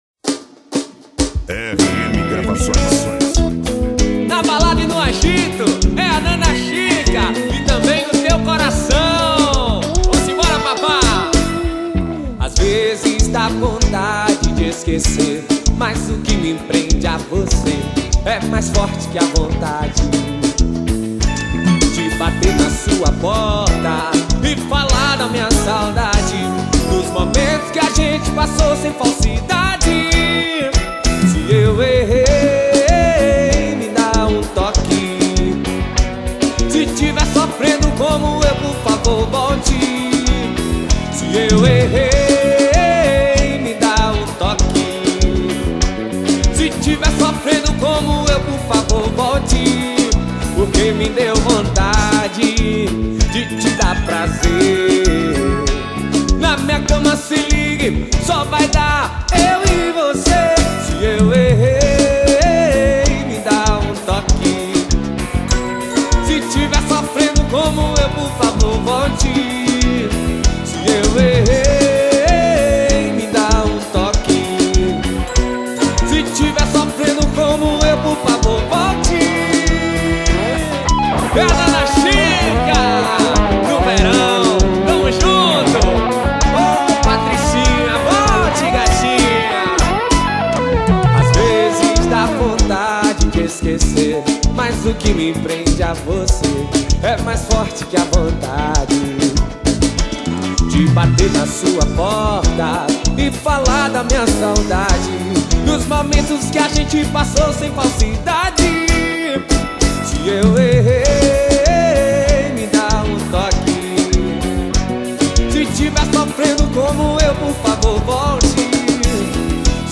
uma banda que toca o ritmo da Bahia